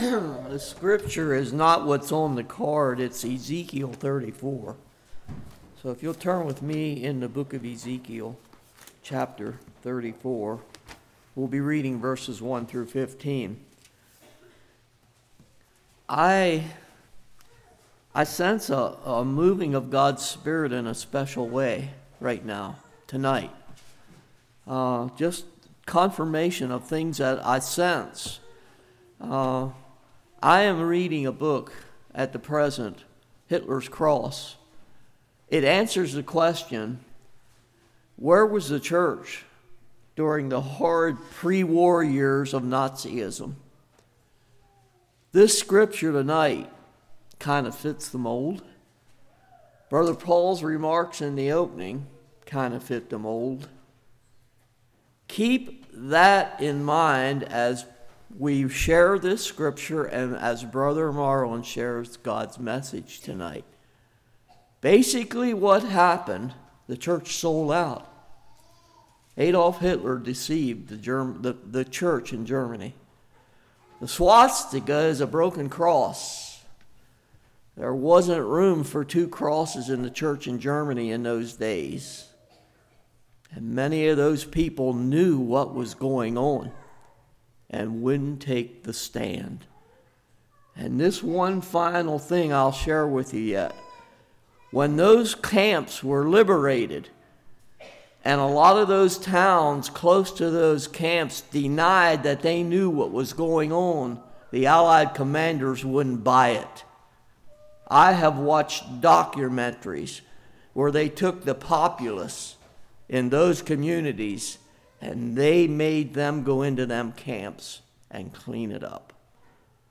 Passage: Ezekiel 34:1-15 Service Type: Revival